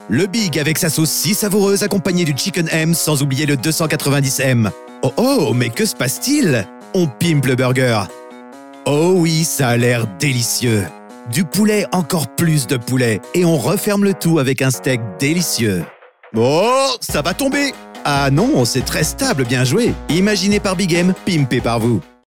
Natural, Travieso, Versátil, Maduro, Amable
Comercial
Indulge yourself with the legendary recording studio sound.
You'll receive mastered TV/Radio broadcast quality files, recorded to sound perfect on any device (PC, Laptop, Smartphone...)